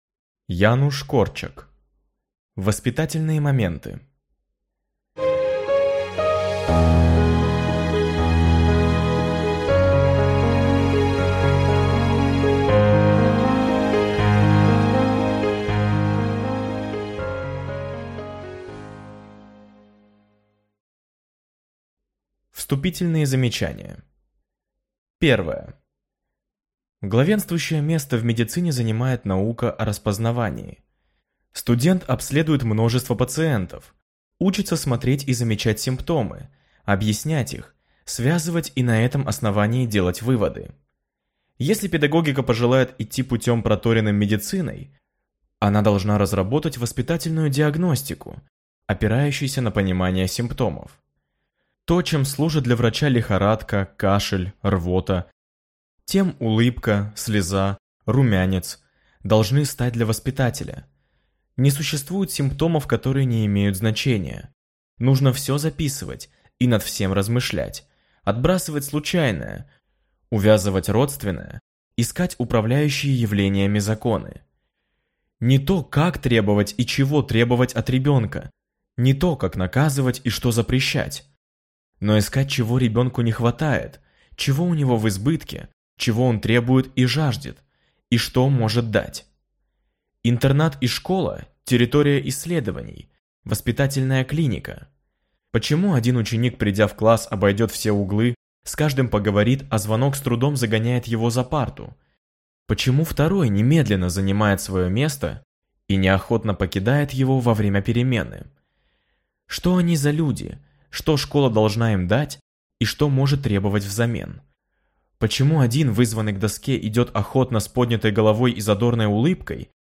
Аудиокнига Воспитательные моменты | Библиотека аудиокниг